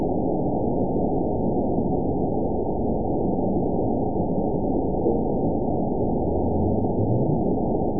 event 922650 date 02/26/25 time 21:43:01 GMT (3 months, 2 weeks ago) score 8.62 location TSS-AB04 detected by nrw target species NRW annotations +NRW Spectrogram: Frequency (kHz) vs. Time (s) audio not available .wav